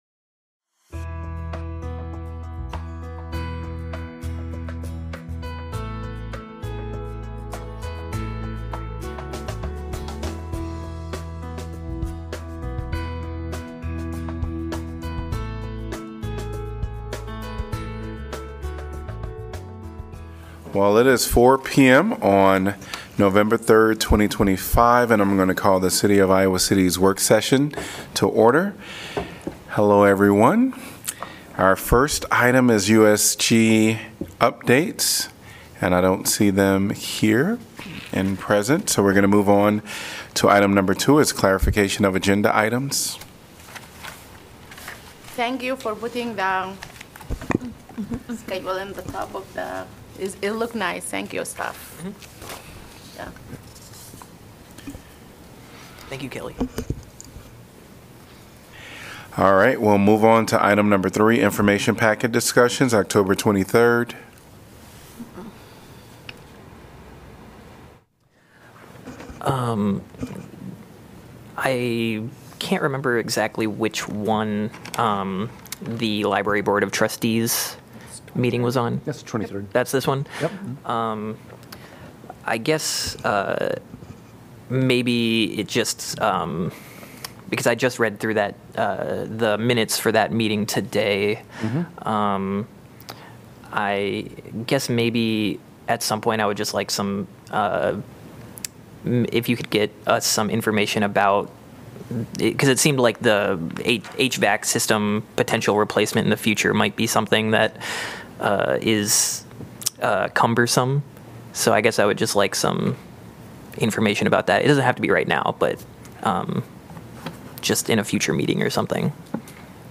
Coverage of the Iowa City Council work session..